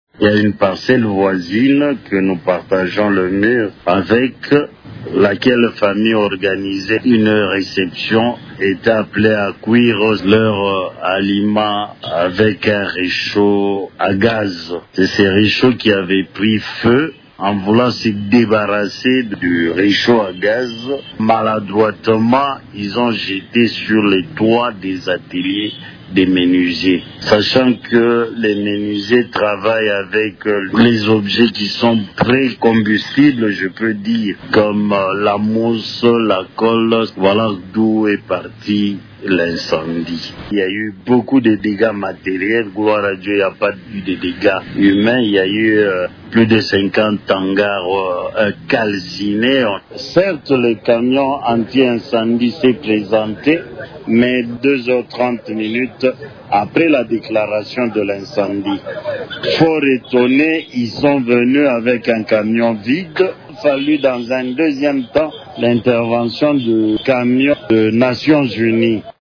Une des victimes
09.kinshasatemoignageincendiemeteo-00.mp3